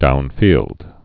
(dounfēld)